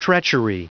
Prononciation du mot treachery en anglais (fichier audio)
Prononciation du mot : treachery